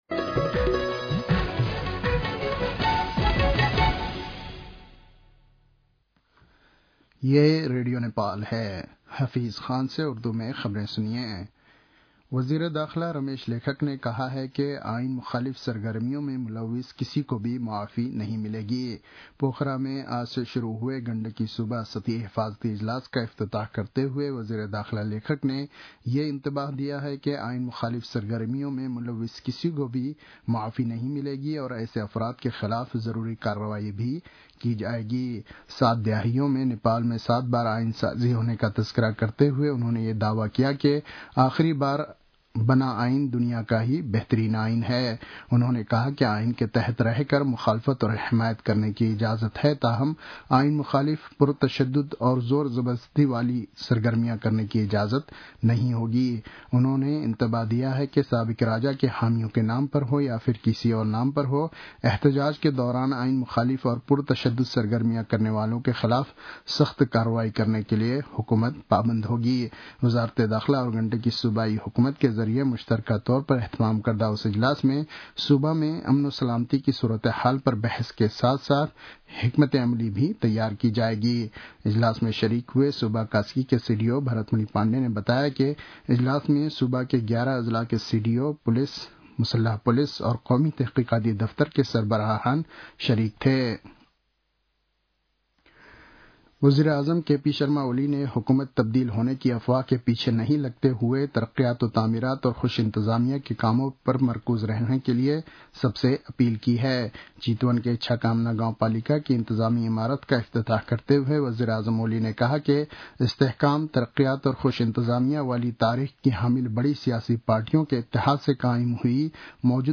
उर्दु भाषामा समाचार : ५ वैशाख , २०८२